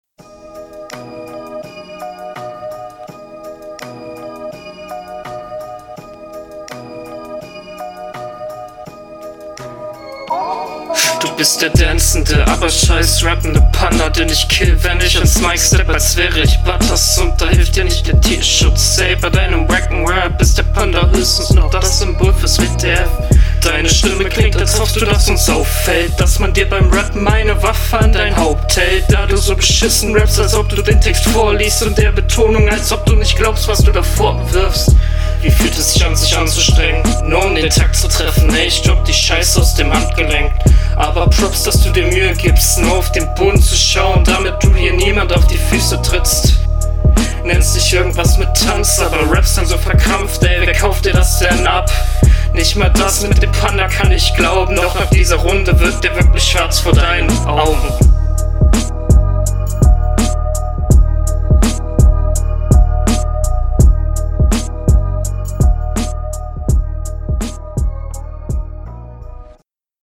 Durch die Dopplung wird es leicht matschig.
Schon wieder n schöner Beat. Mische leider sehr unangenehm.